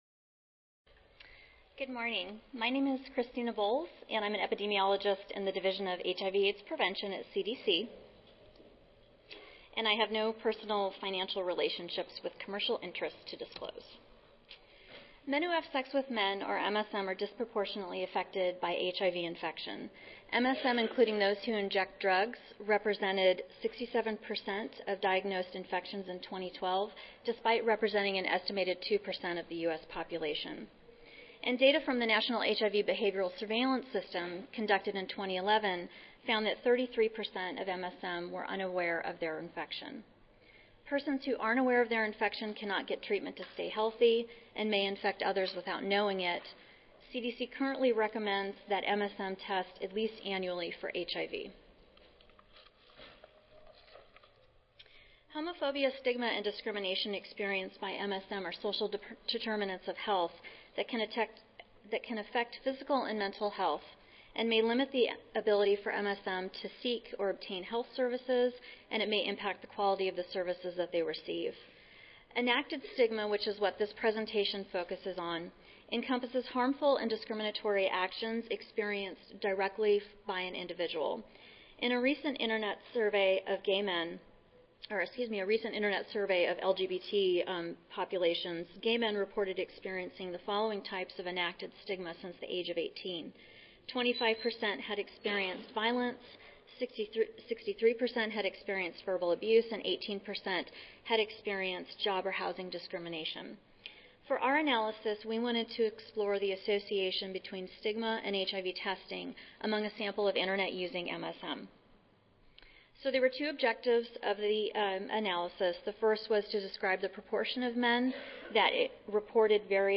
142nd APHA Annual Meeting and Exposition (November 15 - November 19, 2014): Stigma and recent HIV testing among a nationwide survey of U.S. internet-using men who have sex with men